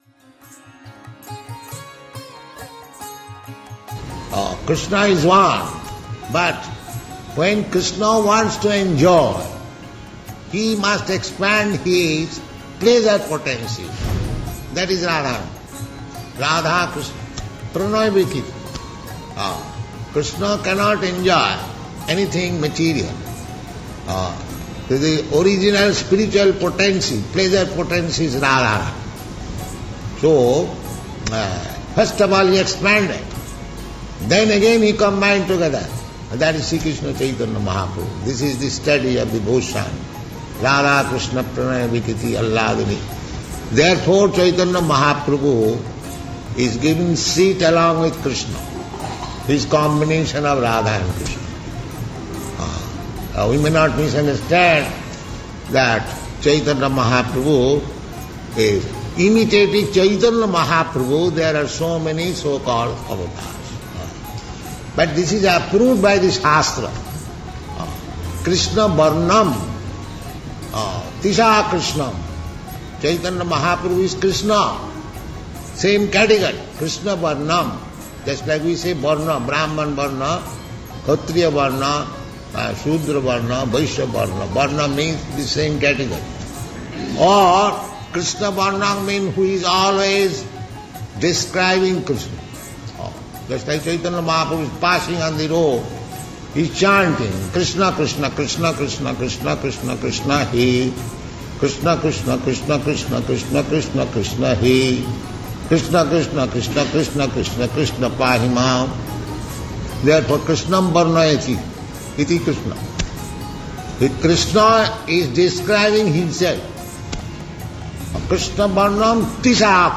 (730306 - Lecture BG 10.01-3 - Calcutta)